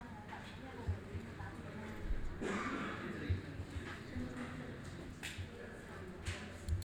1. 环境噪音--最常见
麦克风本身的底噪其实很小，你听到的很多杂音，大多是环境里的声音。
环境音.m4a